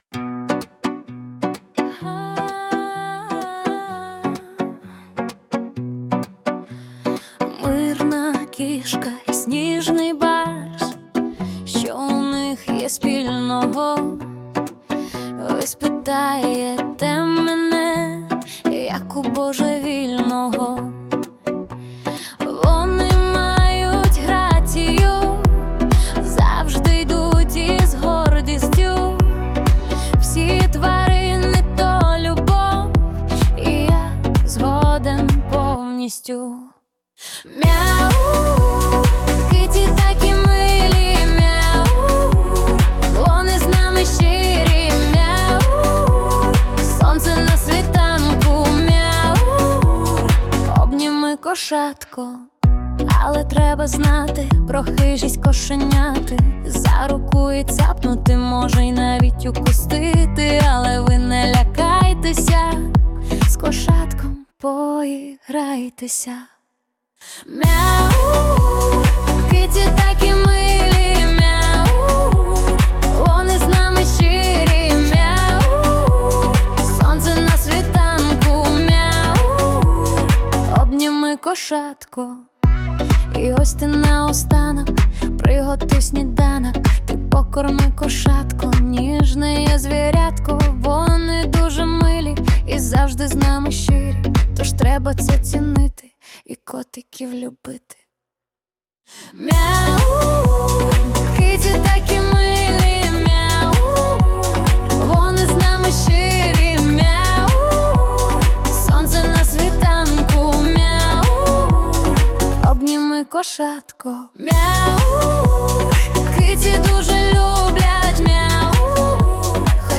Весела пісня про домашніх улюбленців
Стиль: Поп